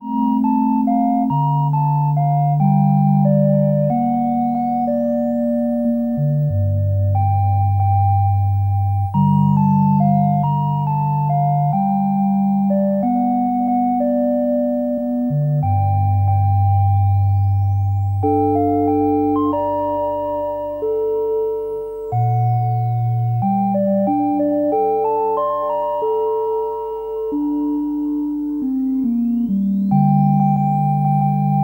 G minor